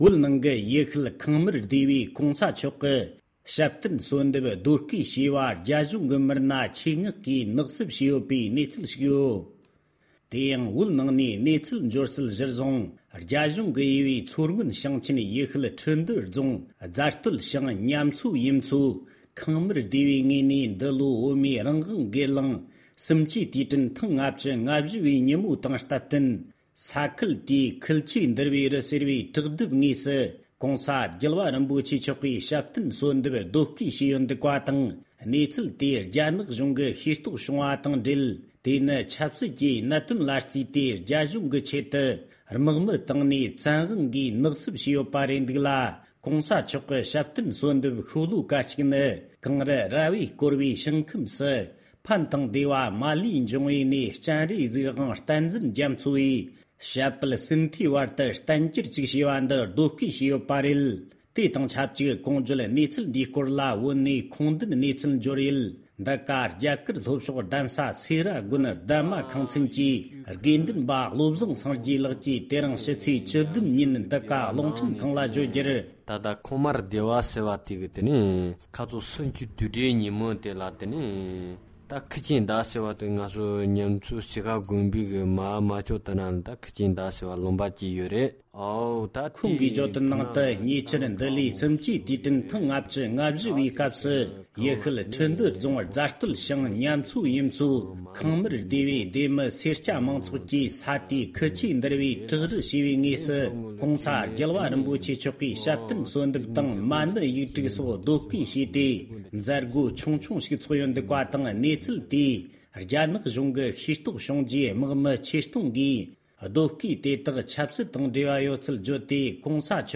གནས་ཚུལ་ཕྱོགས་སྒྲིག་ཞུས་པར་གསན་རོགས༎